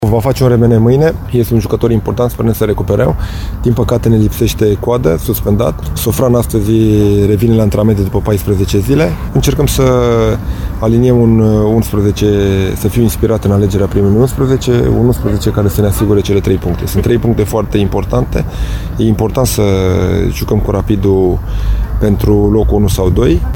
Alb-violeții au plecat deja spre Moldova, iar antrenorul lor, Dan Alexa, vorbește despre problemele de lot: